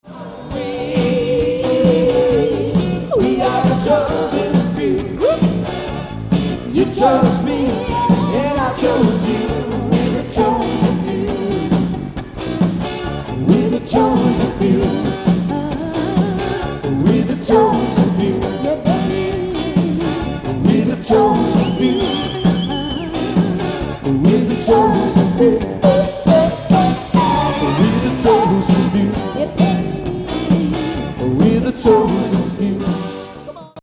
chart topping eighties family group.
live band introduction and solos live